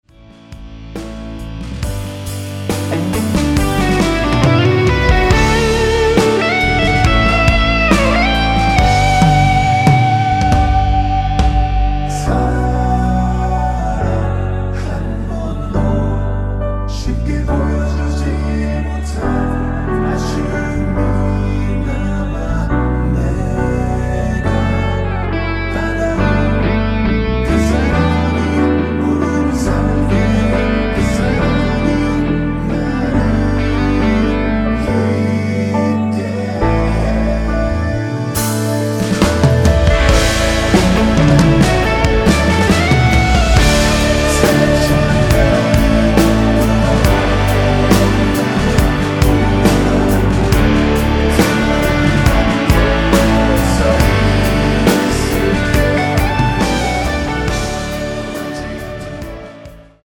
원키에서(-2)내린 코러스 포함된 MR입니다.(미리듣기 확인)
앞부분30초, 뒷부분30초씩 편집해서 올려 드리고 있습니다.
중간에 음이 끈어지고 다시 나오는 이유는